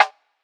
3 Snare -phone.wav